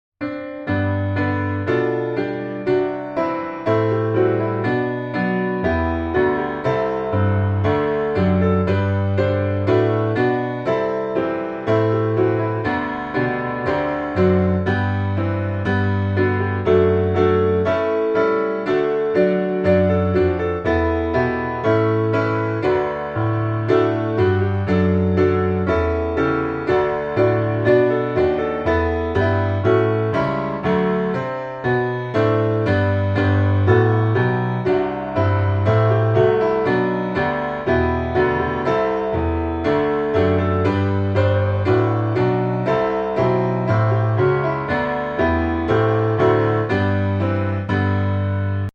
G大調